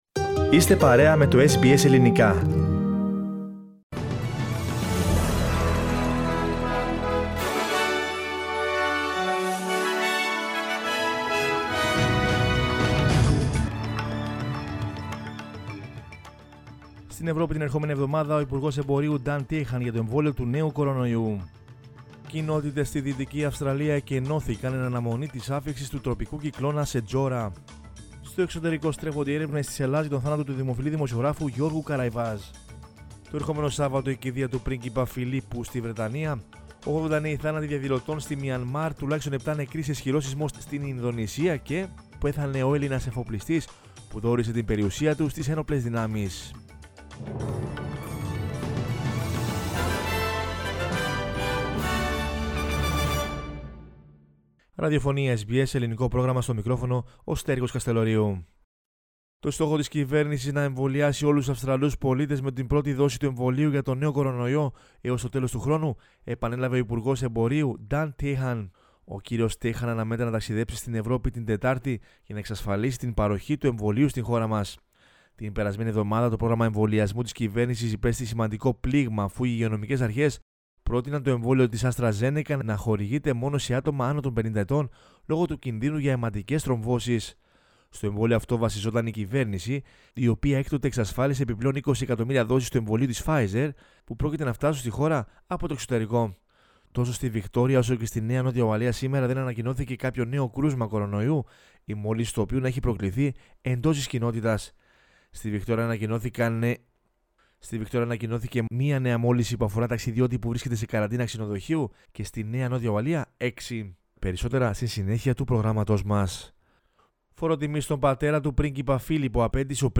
News in Greek from Australia, Greece, Cyprus and the world is the news bulletin of Sunday 11 April 2021.